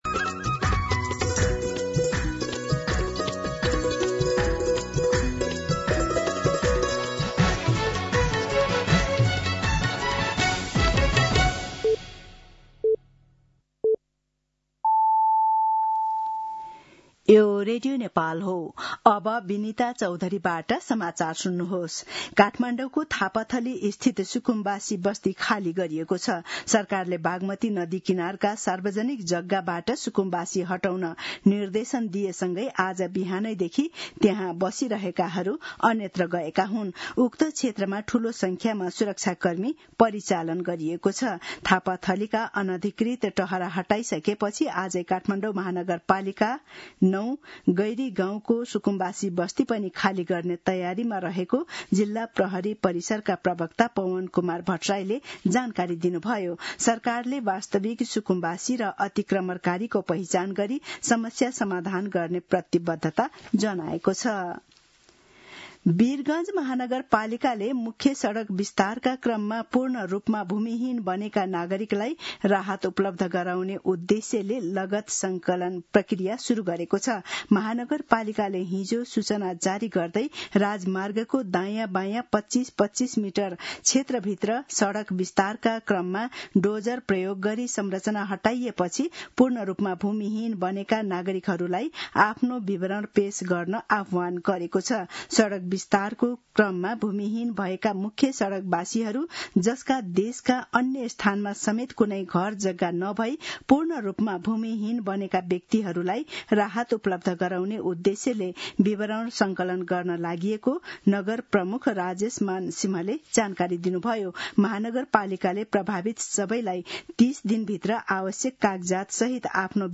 दिउँसो १ बजेको नेपाली समाचार : १२ वैशाख , २०८३